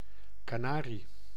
Ääntäminen
US : IPA : [kə.ˈnɛəɹ.i]